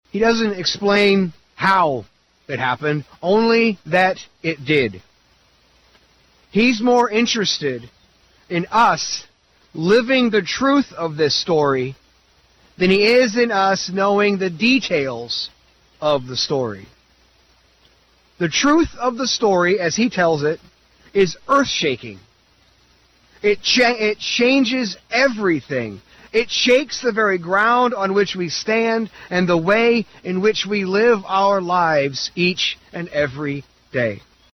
Easter is full of traditions, and a local tradition took place again Sunday morning as Messiah Lutheran Church had its annual drive-in Easter worship service outside the Fairgrounds Anderson Building.